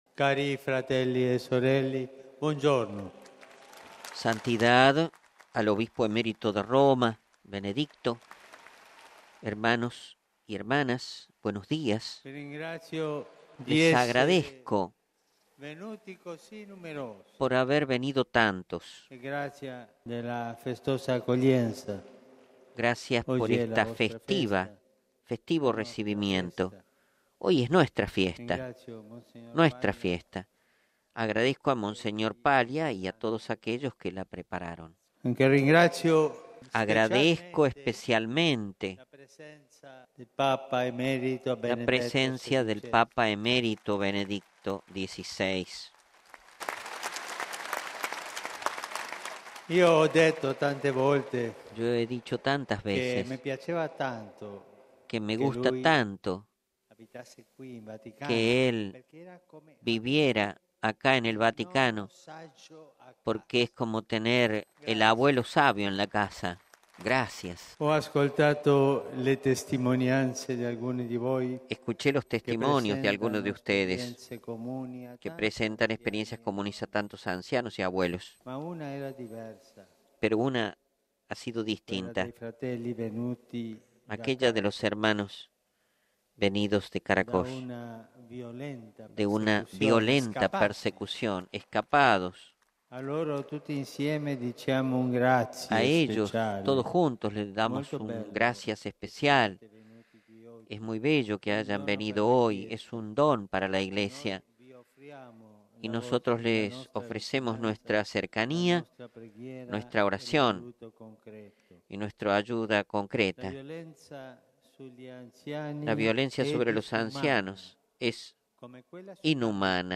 (CdM – RV) AUDIO DE LA RADIO CRÓNICA DE RADIO VATICANO, CON LA VOZ DEL PAPA Texto completo del discurso del Papa: ¡Queridos hermanos y hermanas, buenos días!